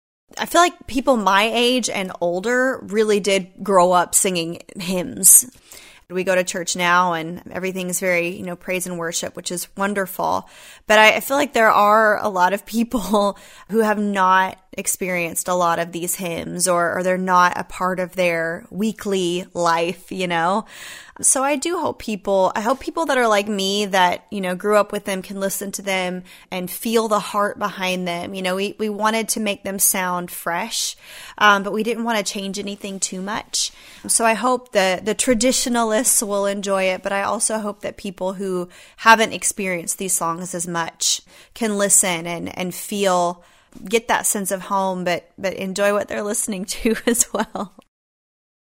Carrie Underwood talks about introducing new generations to the hymns featured on her album, My Savior.